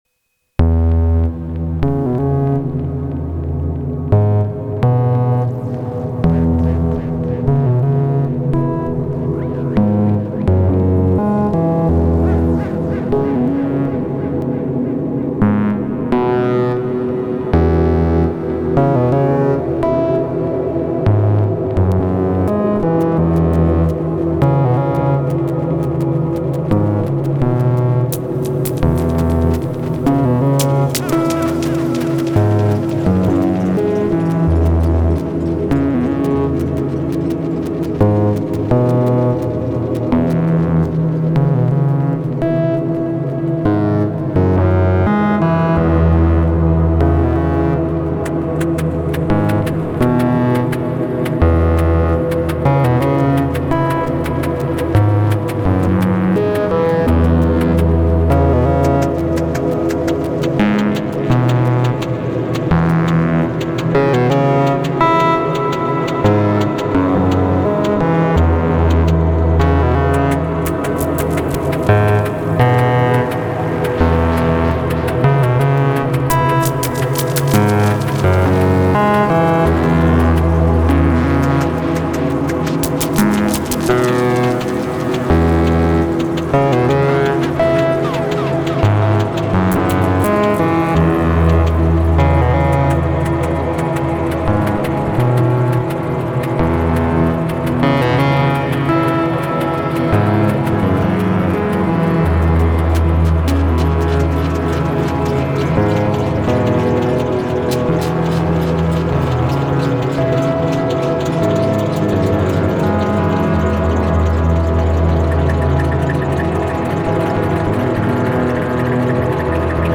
Ambient with the Pulsar 23 :blush: